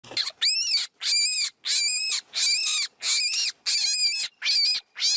Звуки кроликов: крик кролика